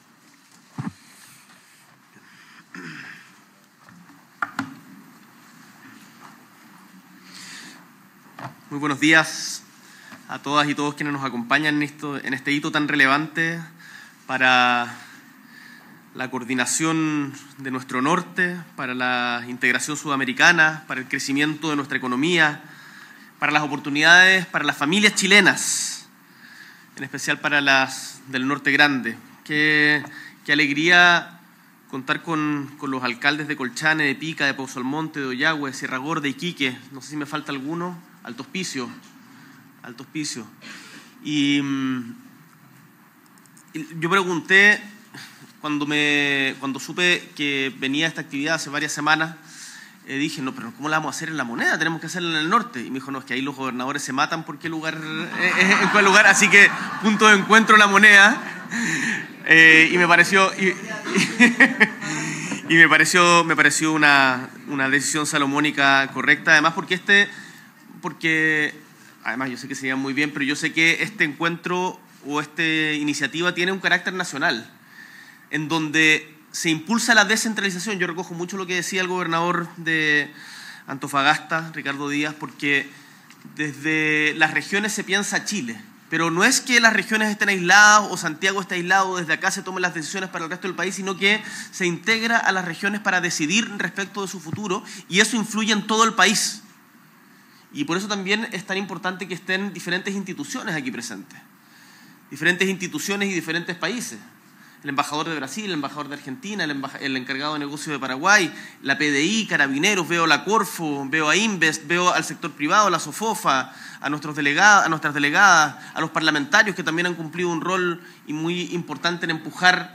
S.E. el Presidente de la República, Gabriel Boric Font, encabeza presentación del Plan de Acción del Corredor Bioceánico Vial